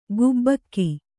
♪ gubbakki